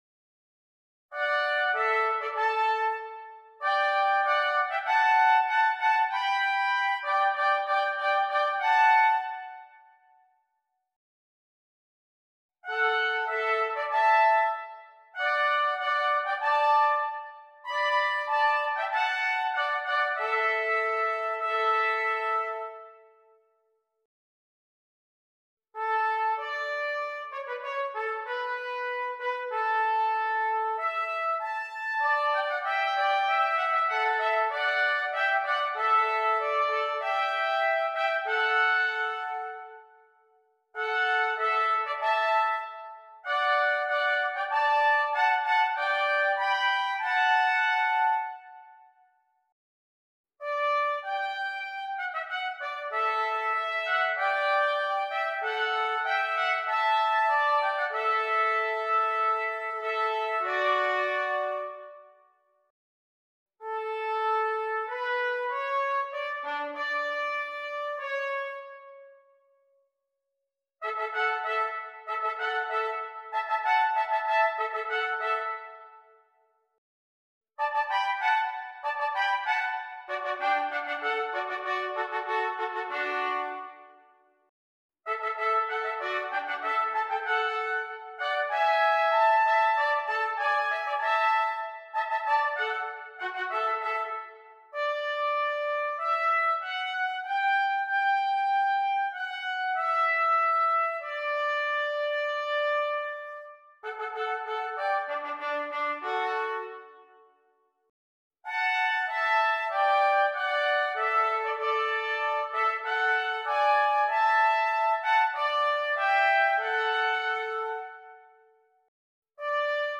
2 Trumpets